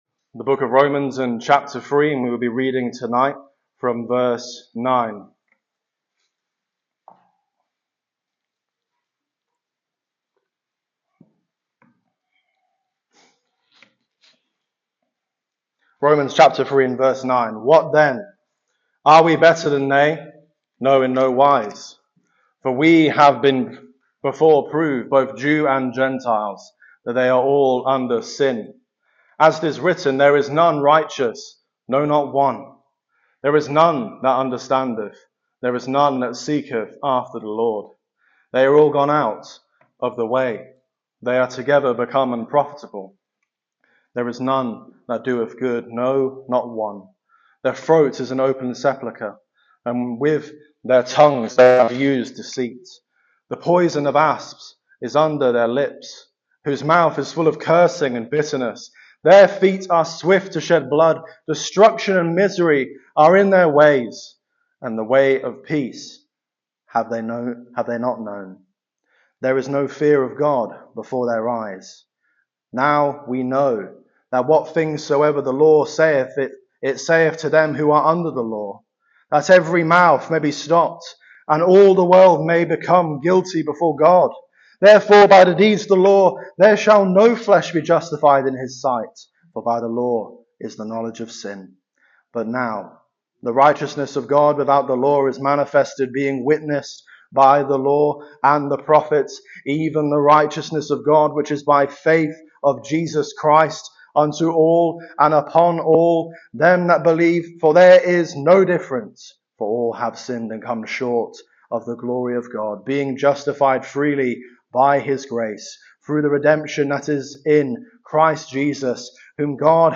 Gospel Messages